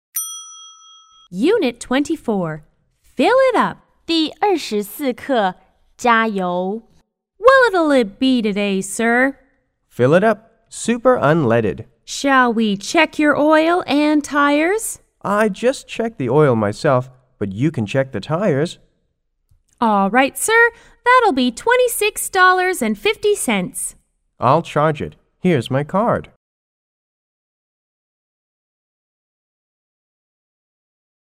S= Service Attendant  D= Driver